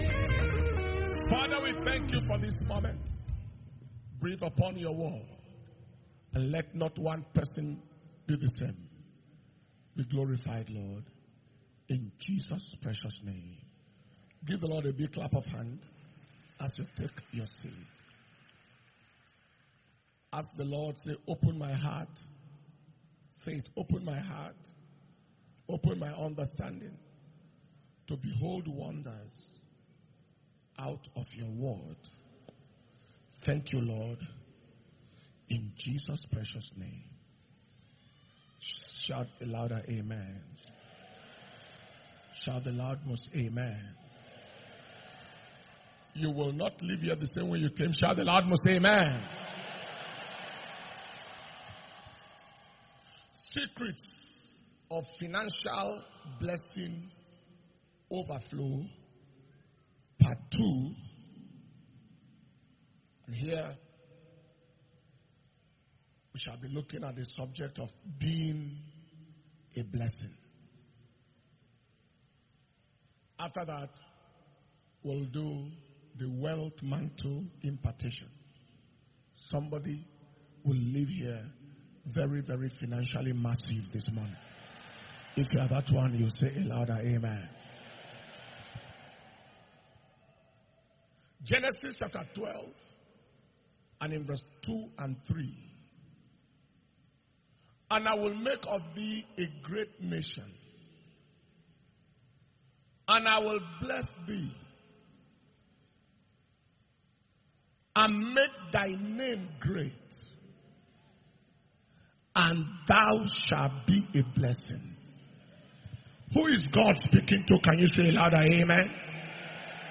March 2022 Impartation Service – Sunday 20th March 2022